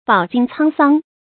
bǎo jīng cāng sāng
饱经沧桑发音
成语正音沧，不能读作“chuānɡ”。